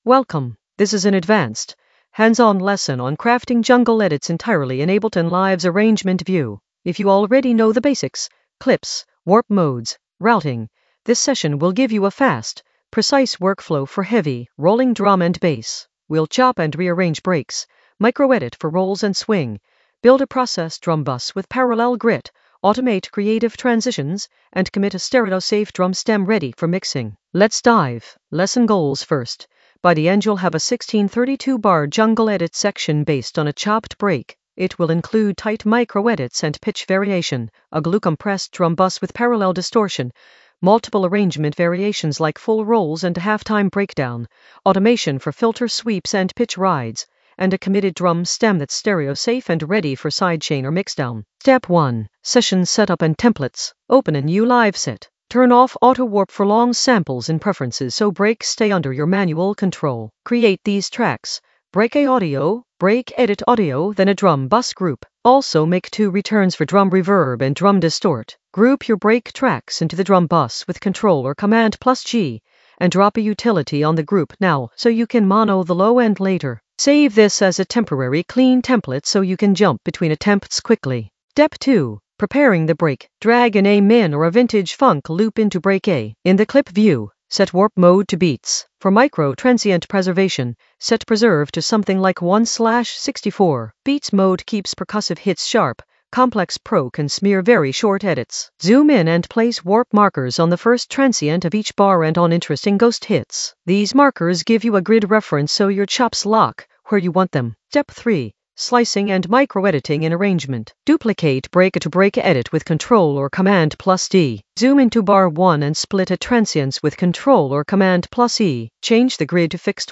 An AI-generated advanced Ableton lesson focused on Advanced jungle edit workflow in Arrangement View in the Drums area of drum and bass production.
Narrated lesson audio
The voice track includes the tutorial plus extra teacher commentary.